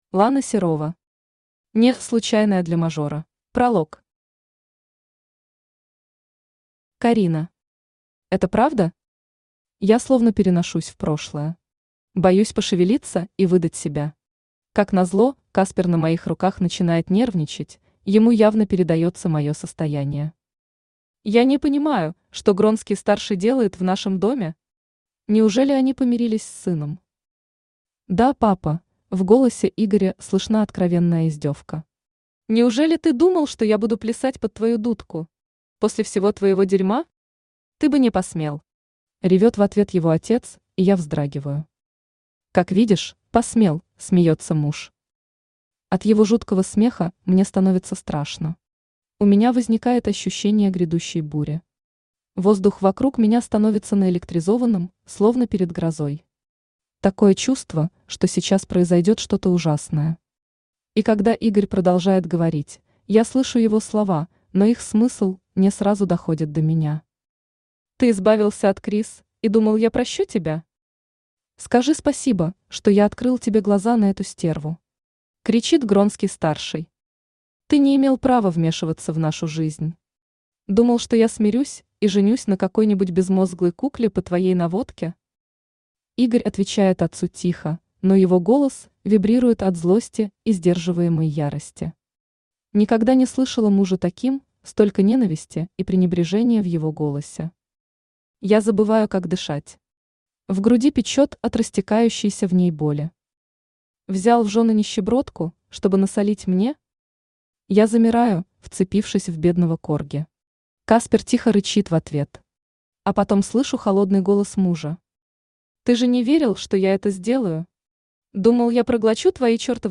Аудиокнига (не) Случайная для мажора | Библиотека аудиокниг
Aудиокнига (не) Случайная для мажора Автор Лана Серова Читает аудиокнигу Авточтец ЛитРес.